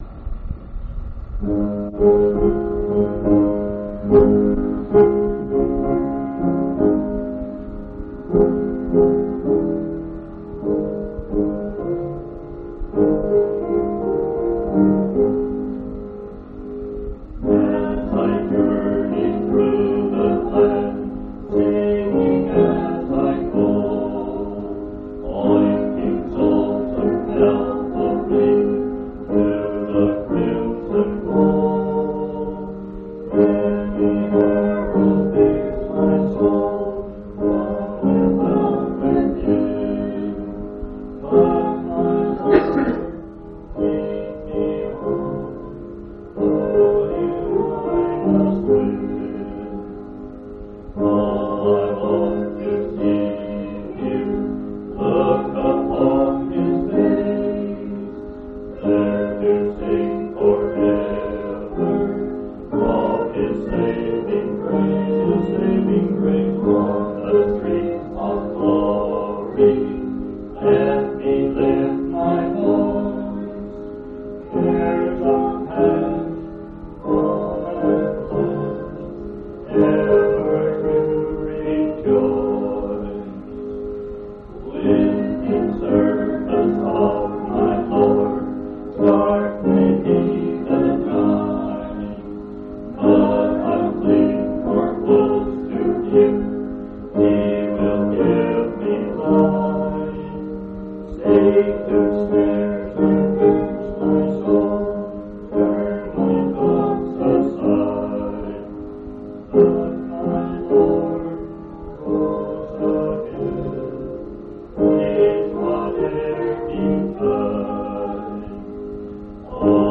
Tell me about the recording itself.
9/23/1979 Location: Grand Junction Local Event